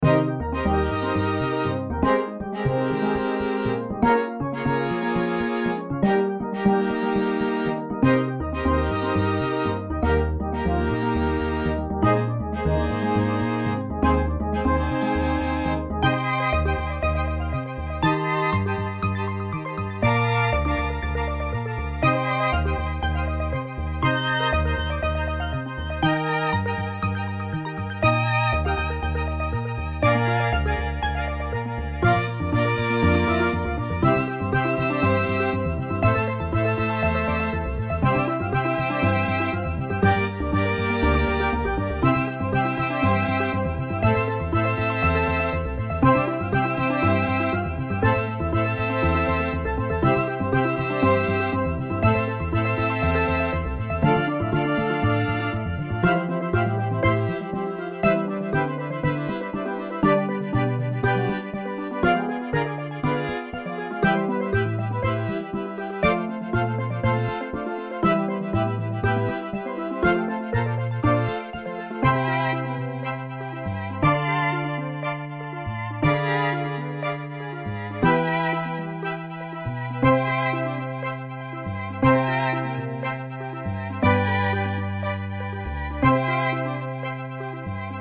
BGM
スティールドラム、アコースティックベース、チェロ